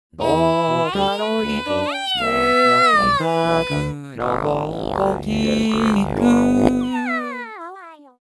デモ版はア行カ行とボ、ロ、ラ、ドしか発音できないので歌詞とかは適当にでっち上げてます。素直でボーイッシュな声が好印象。女声コーラスもかわいい感じ